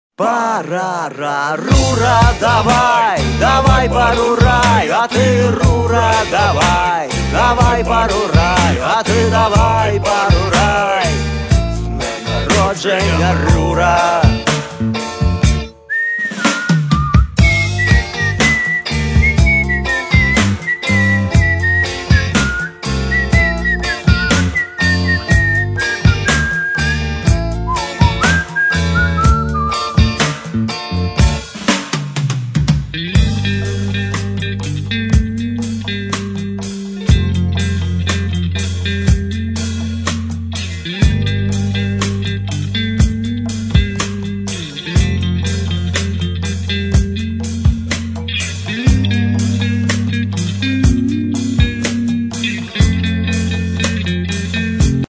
Rock (320)